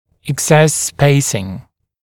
[ɪk’ses speɪsɪŋ] [ek-][ик’сэс ‘спэйсин] [эк-]избыточное пространство, свободные промежутки между зубами